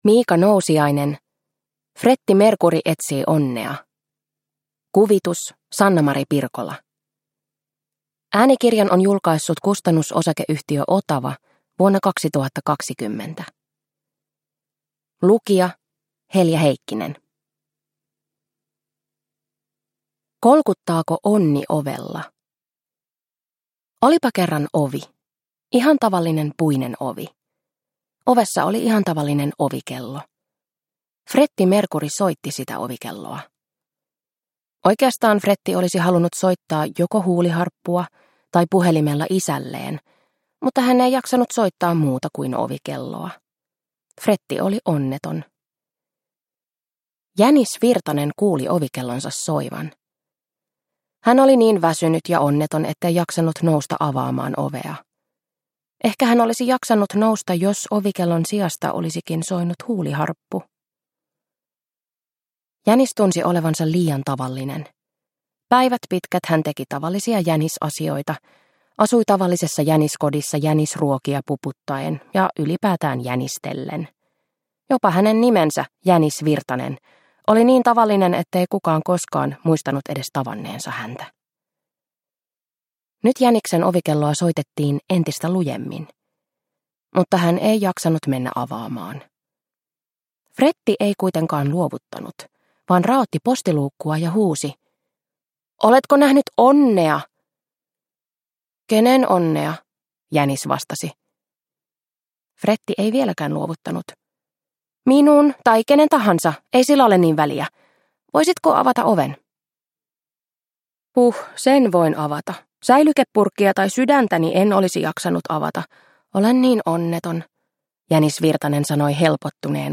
Fretti Mercury etsii onnea – Ljudbok – Laddas ner